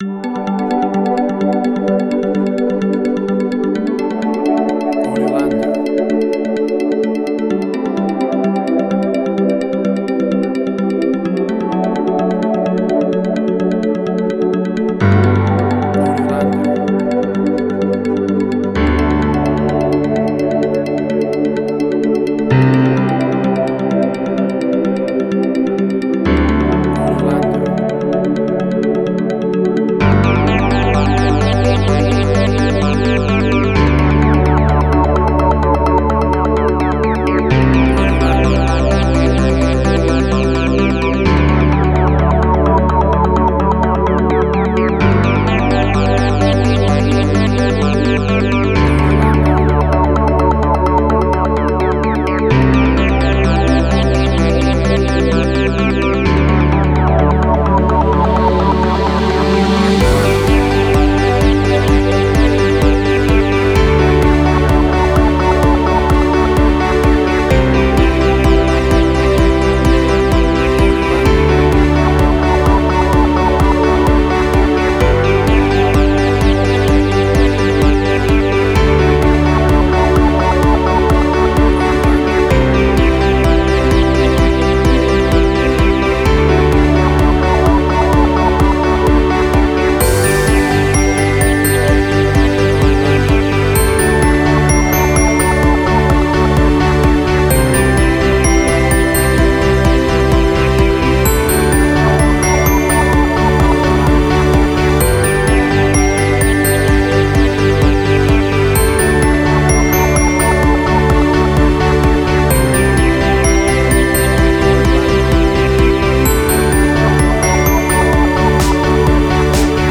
Tempo (BPM): 128